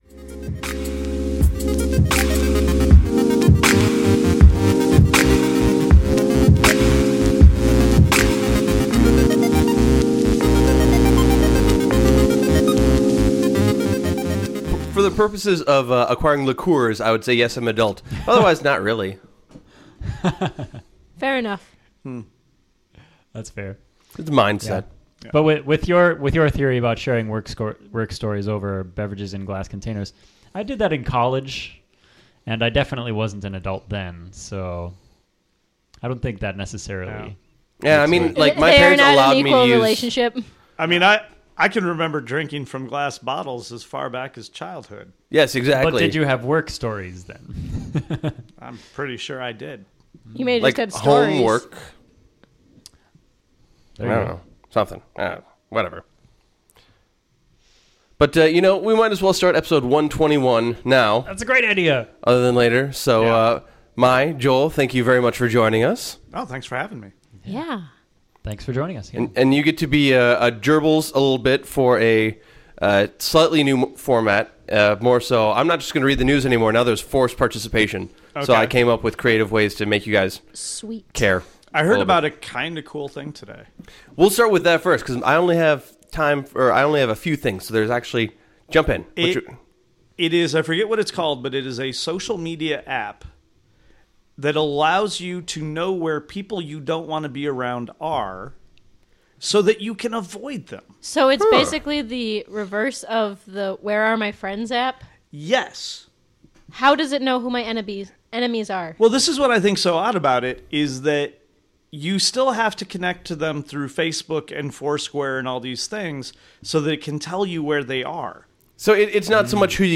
Taped at The Wheels Brewing Co. Studio, Minneapolis, MN on March 18, 2014.